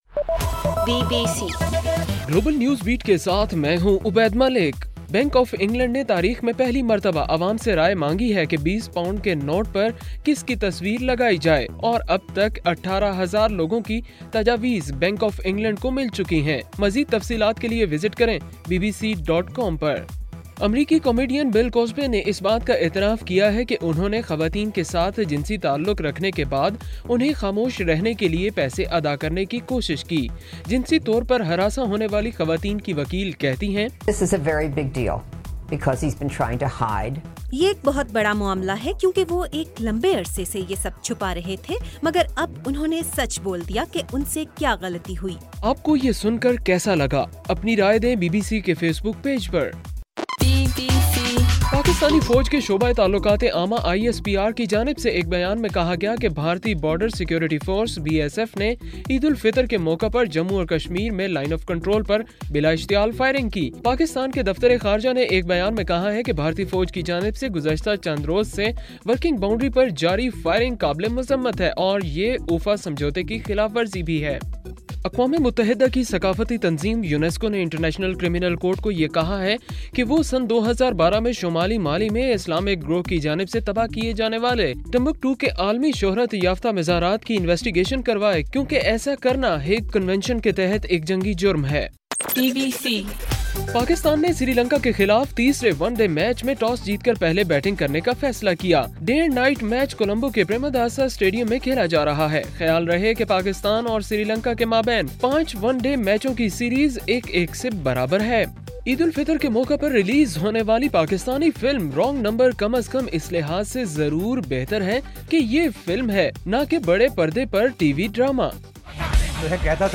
جولائی 19: رات 10 بجے کا گلوبل نیوز بیٹ بُلیٹن